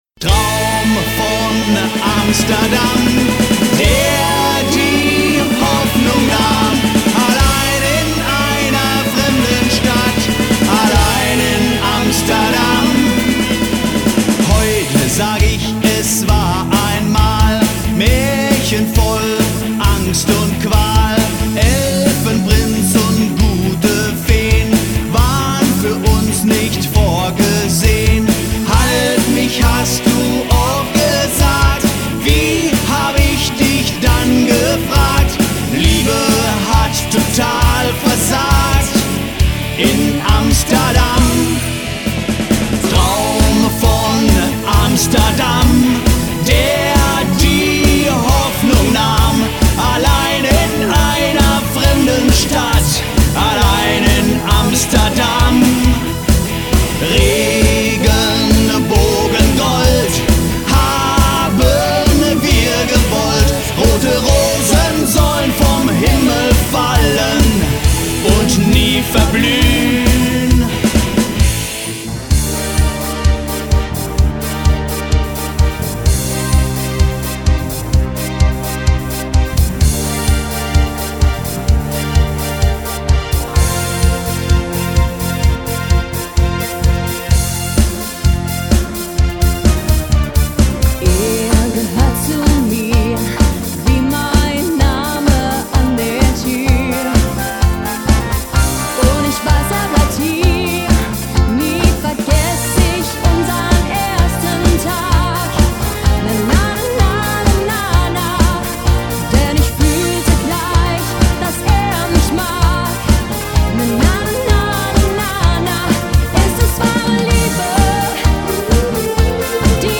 "Schlagermix"